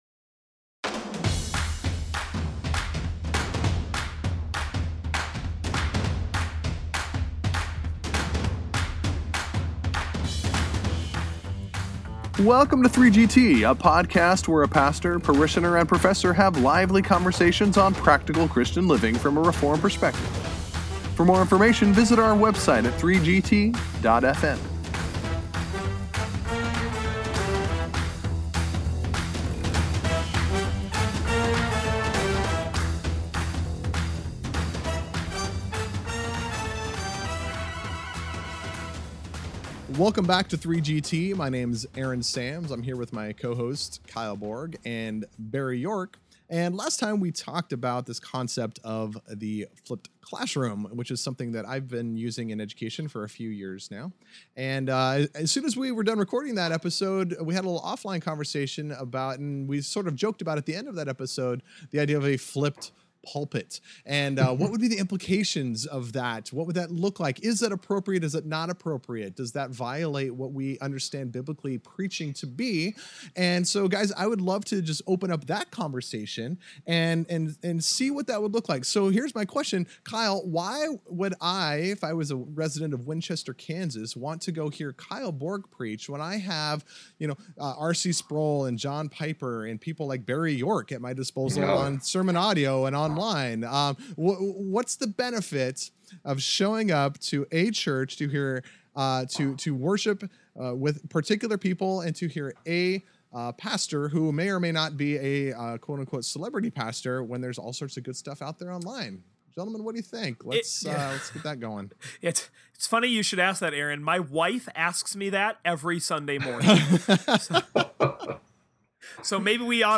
Join the pastor, parishioner, and prof as they have a rollicking discussion about the dynamic of worship, the place of the local church, and the Spirit’s special use of the foolishness of gospel preaching by your minister each Lord’s Day morning!